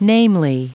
Prononciation du mot namely en anglais (fichier audio)
Prononciation du mot : namely